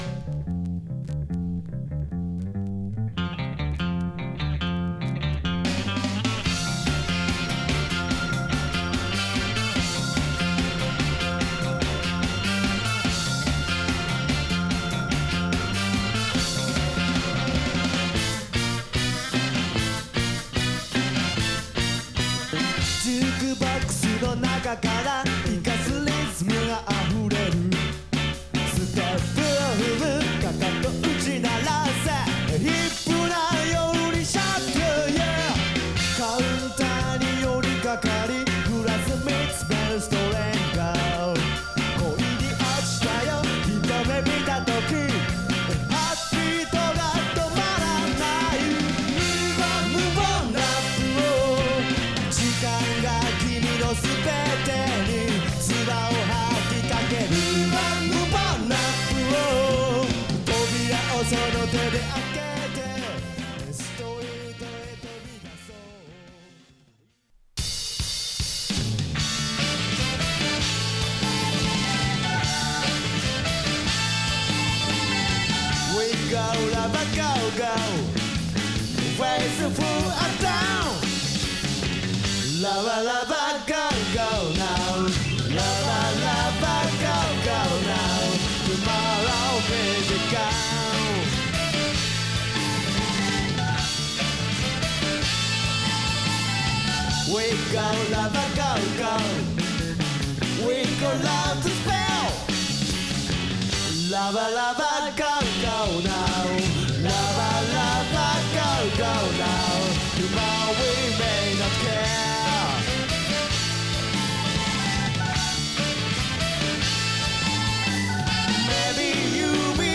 畳み掛け系５曲入り